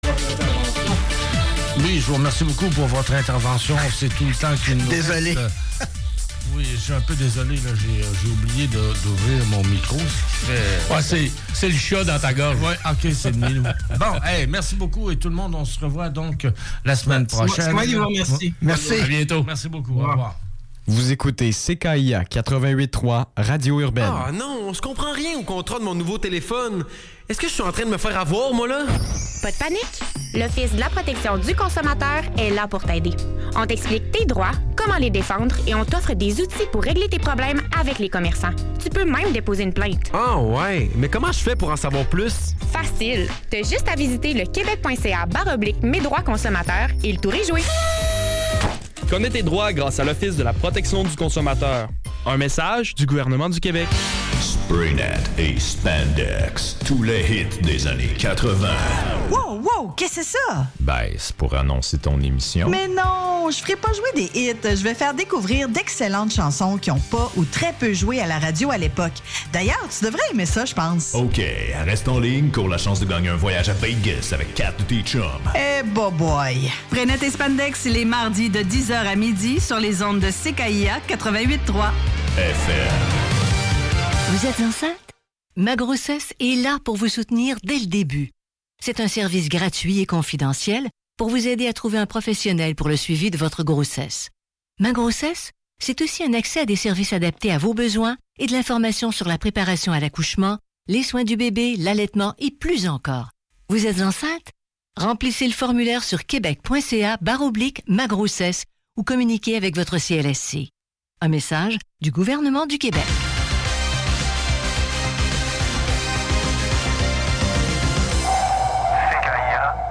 CKIA 88,3 FM - Radio urbaine